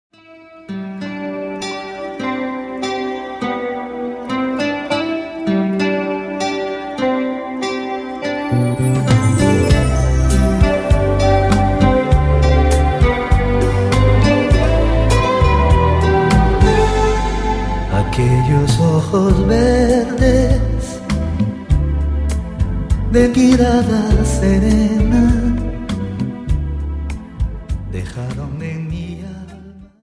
Dance: Rumba Song